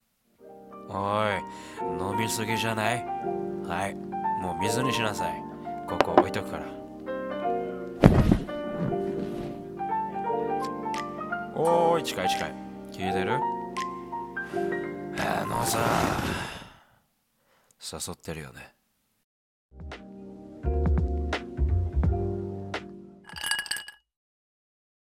誘ってるよね 【シチュボ 1人声劇】